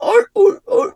seal_walrus_2_bark_06.wav